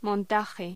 Locución: Montaje
Sonidos: Hostelería